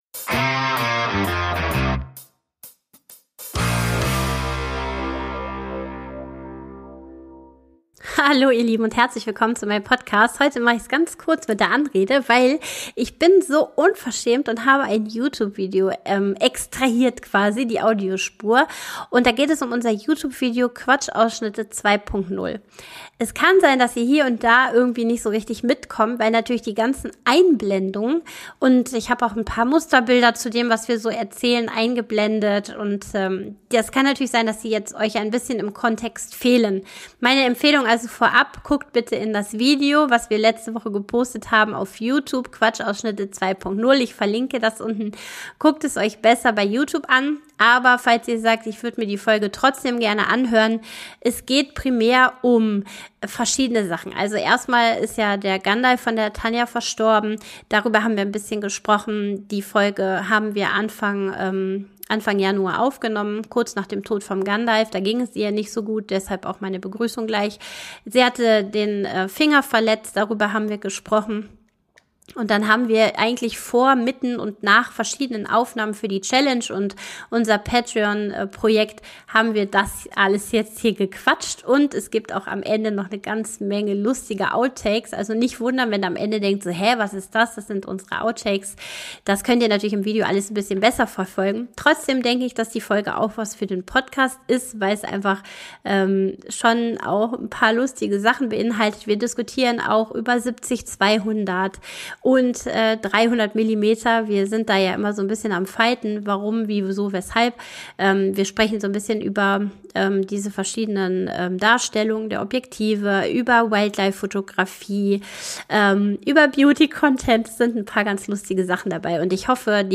(Audiospur YouTube Video)